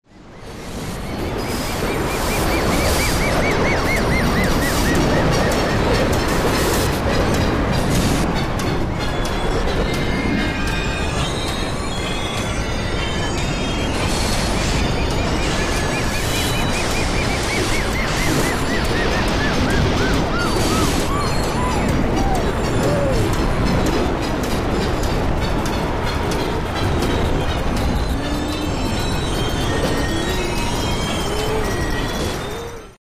Ultimately we ended up with a three part piece, a sonic journey that took us from the city (city sounds) to the country (animal and insect sounds) and finally to outer space (warped city sounds and sound effects).
city.mp3